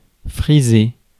Ääntäminen
Ääntäminen France: IPA: [fʁi.ze] Haettu sana löytyi näillä lähdekielillä: ranska Käännöksiä ei löytynyt valitulle kohdekielelle.